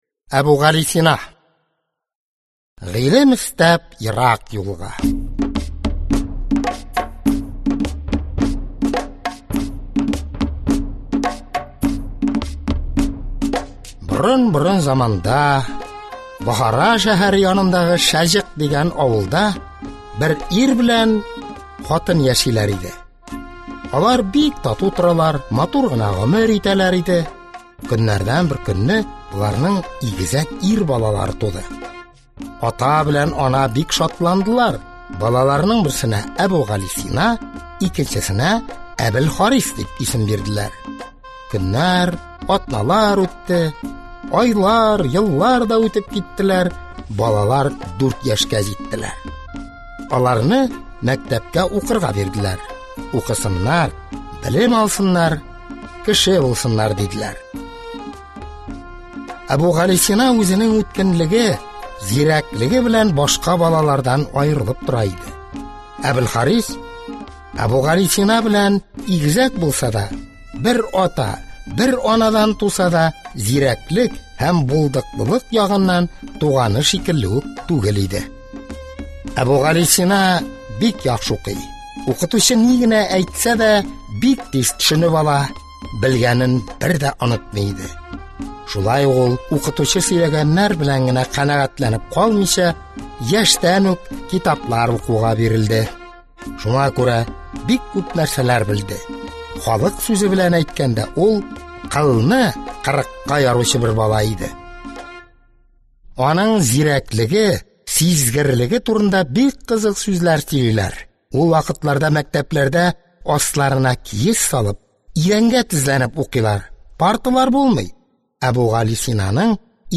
Аудиокнига Әбүгалисина | Библиотека аудиокниг
Прослушать и бесплатно скачать фрагмент аудиокниги